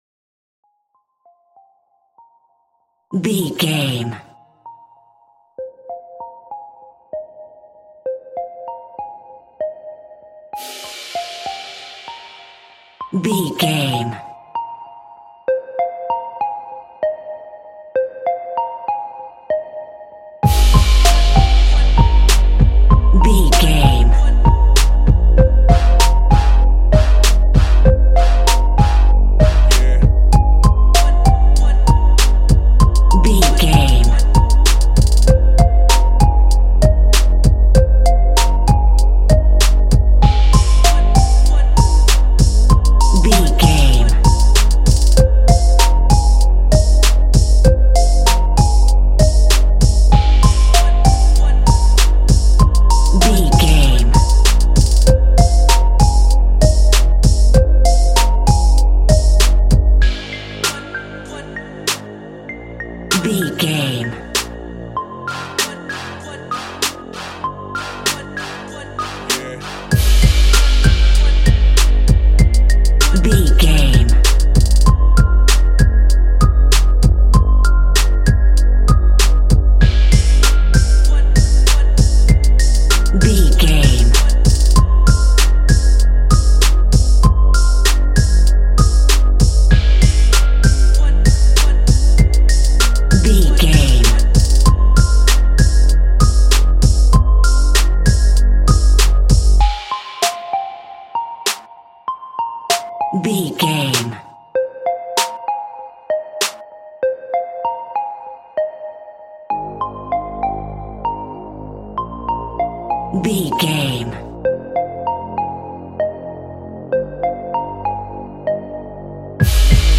A great piece of royalty free music
Aeolian/Minor
SEAMLESS LOOPING?
DOES THIS CLIP CONTAINS LYRICS OR HUMAN VOICE?
chilled
laid back
groove
hip hop drums
hip hop synths
piano
hip hop pads